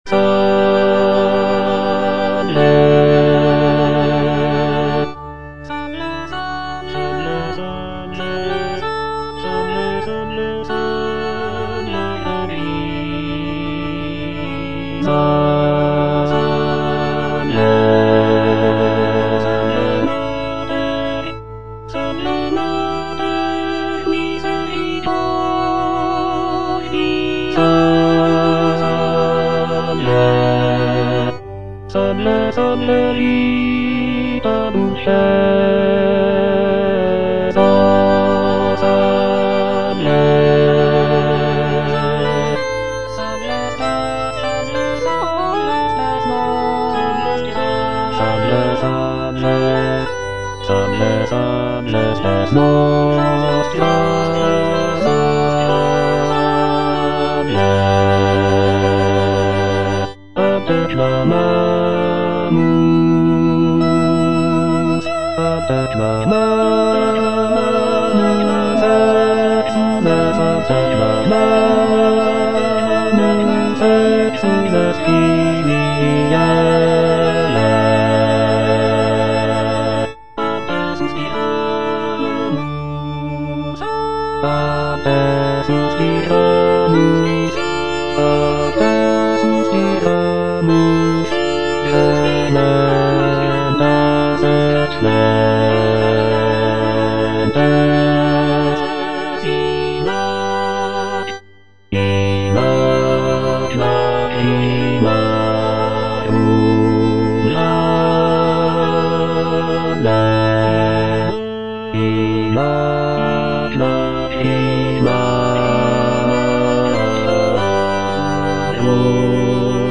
G.F. SANCES - SALVE, REGINA Bass (Emphasised voice and other voices) Ads stop: auto-stop